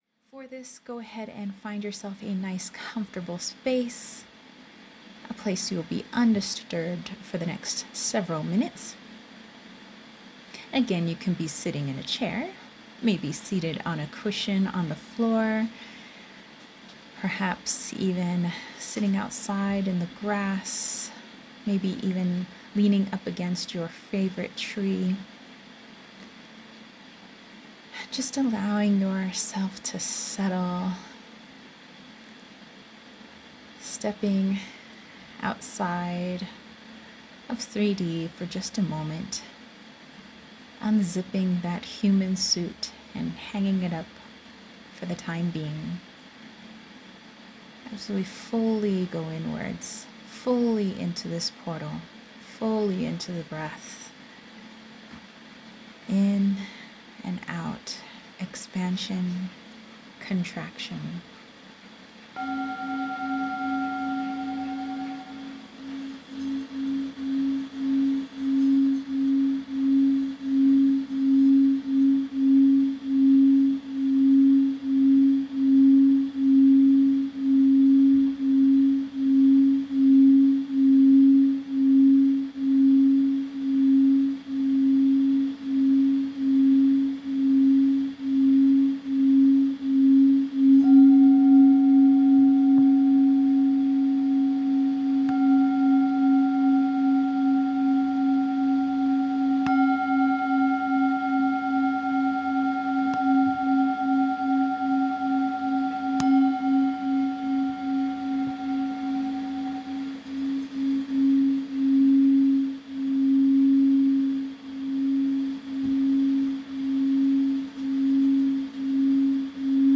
Sample song: